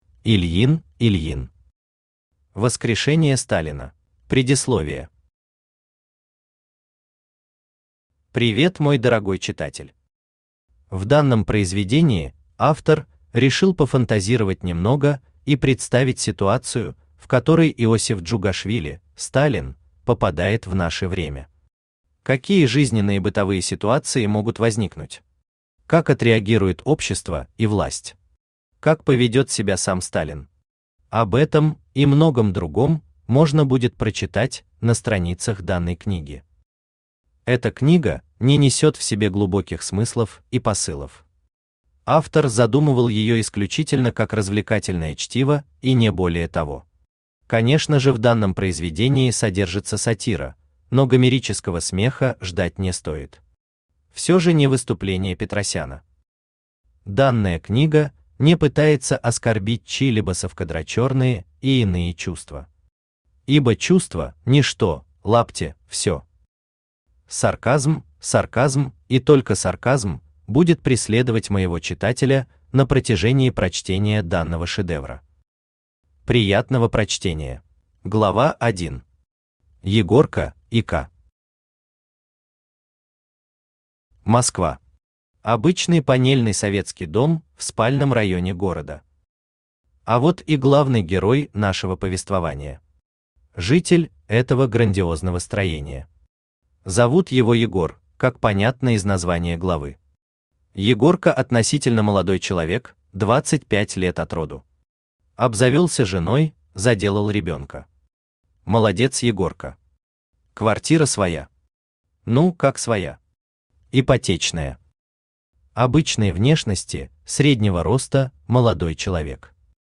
Aудиокнига Воскрешение Сталина Автор Ильин Викторович Ильин Читает аудиокнигу Авточтец ЛитРес.